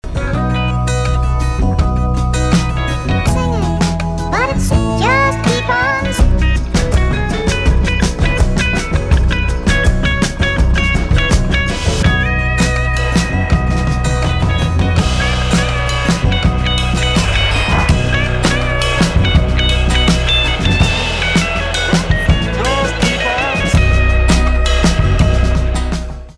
инди
гитара , барабаны , альтернатива
спокойные